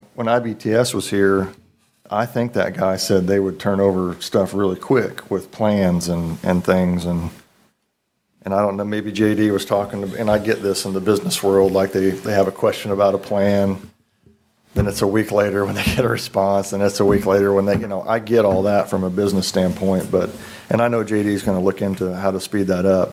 including first ward alderman Drew Green.